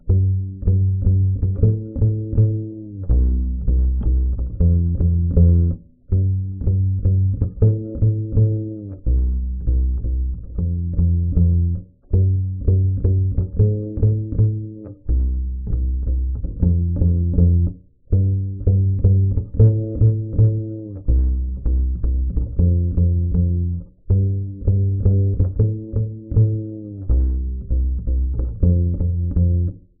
Tag: 低音 寒冷 器乐